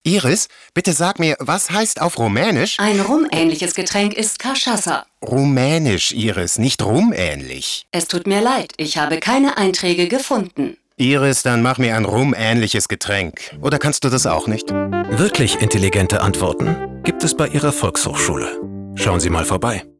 Werbung: VHS (Giesing Team)